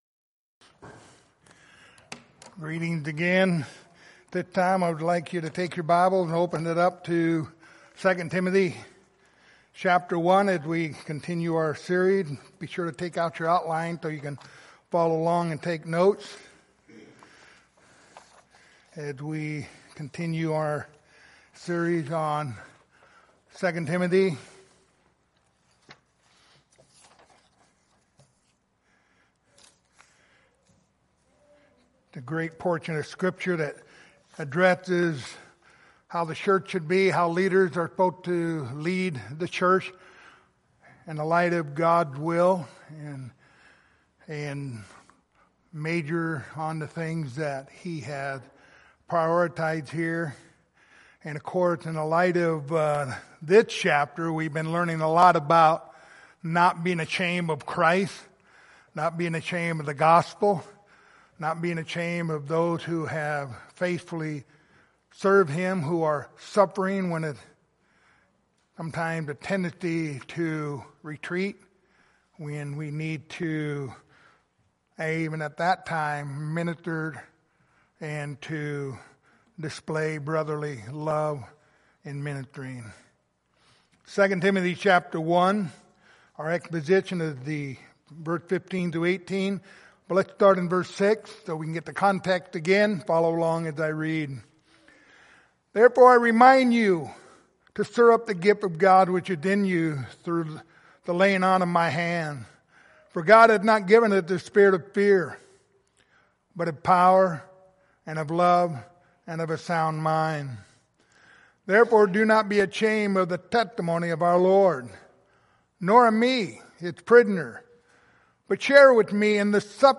Passage: 2 Timothy 1:15-18 Service Type: Sunday Morning